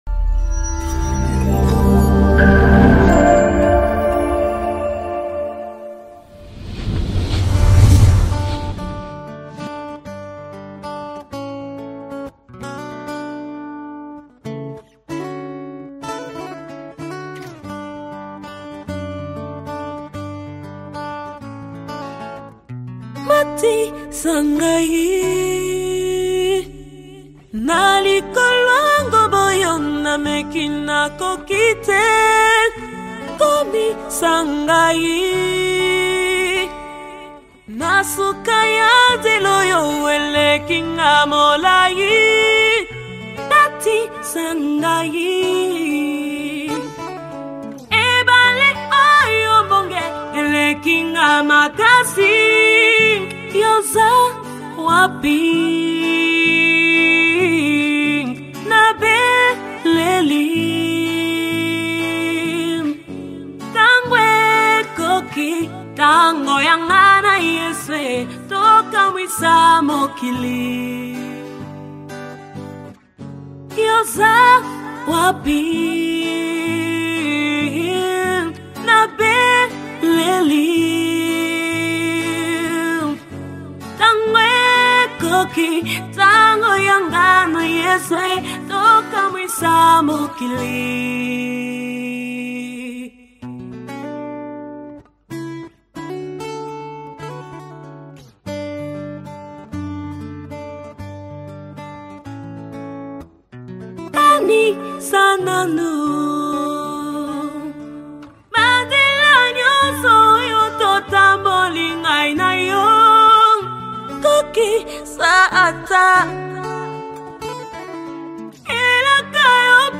worship anthem
CONGOLESE WORSHIP ELEMENTS with CONTEMPORARY GOSPEL SOUNDS